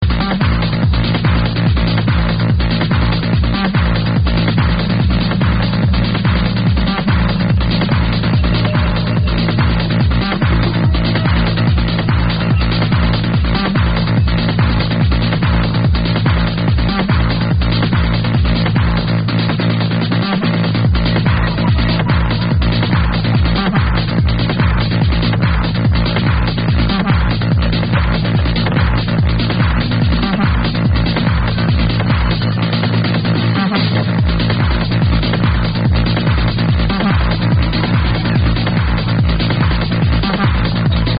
and again unknown techno track